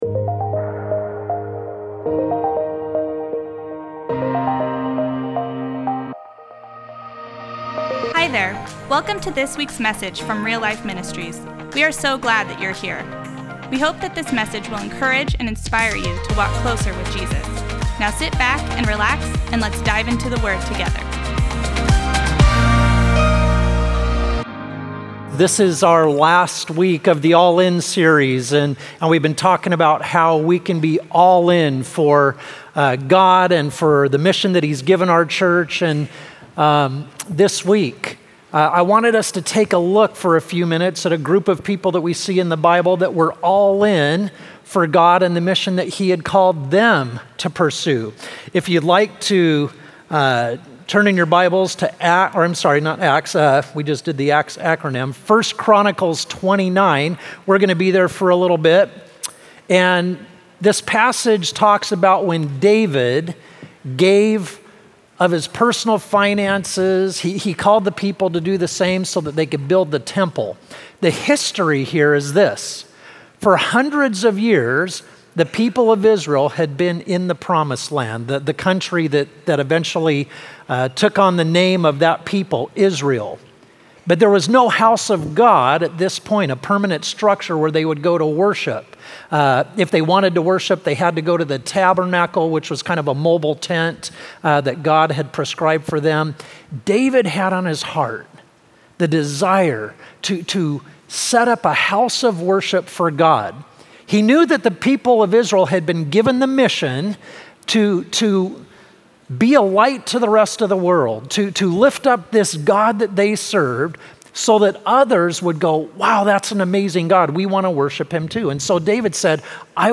1. What about the sermon resonated with you?